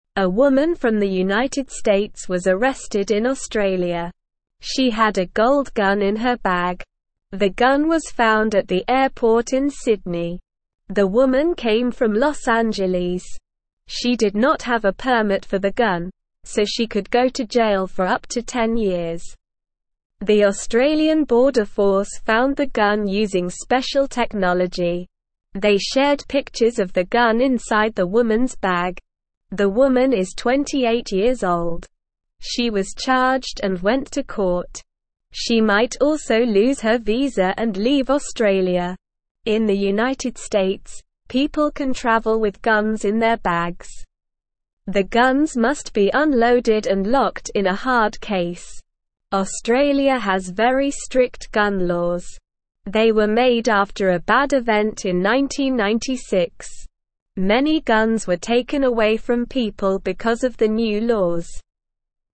Slow
English-Newsroom-Beginner-SLOW-Reading-Woman-Arrested-in-Australia-with-Gold-Gun.mp3